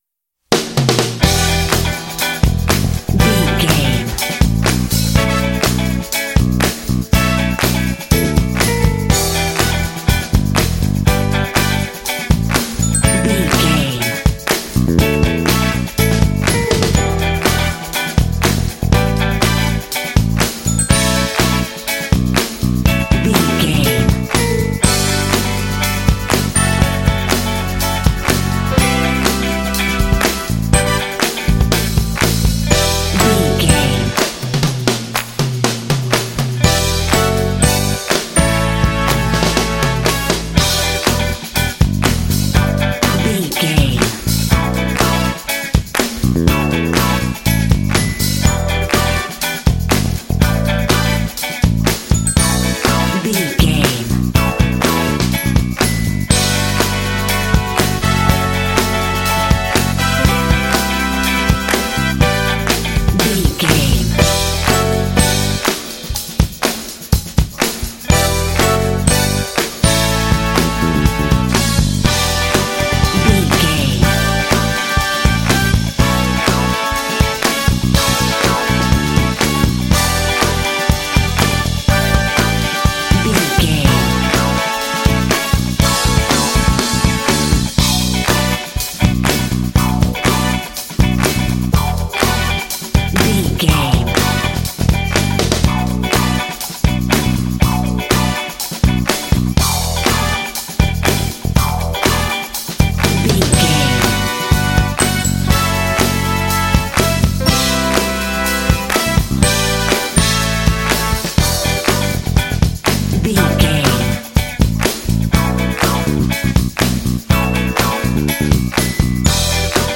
Aeolian/Minor
funky
groovy
driving
energetic
lively
piano
bass guitar
electric guitar
drums
brass